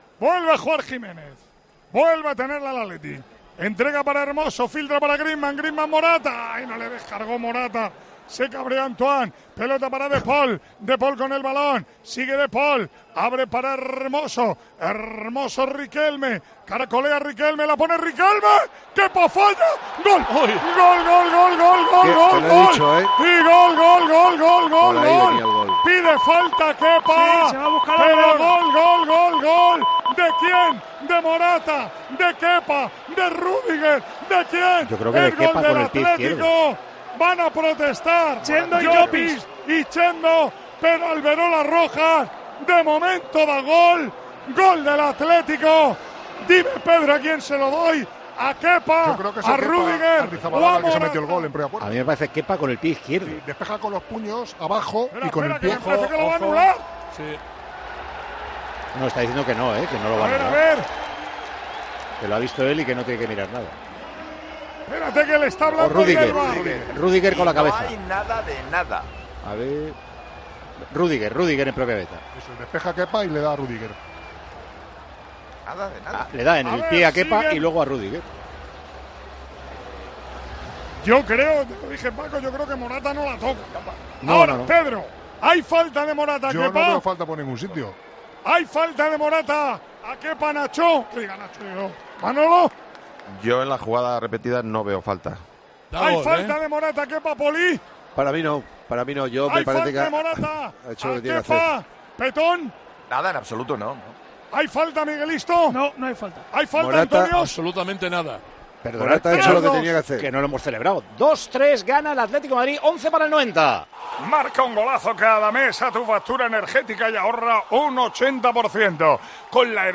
El equipo de COPE, en el Estadio Al-Awwal Park de Arabia Saudí
Así vivimos en Tiempo de Juego la retransmisión del Real Madrid - Atlético de Madrid